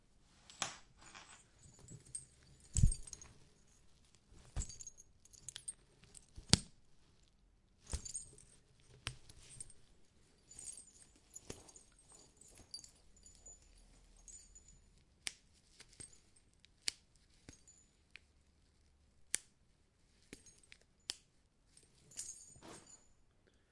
描述：在这里，我试图收集我在家里发现的所有扣件。其中大部分在夹克衫上，一个手提包里有啷个球，还有一些雪裤。
Tag: 点击 服装和-配件 扣紧固件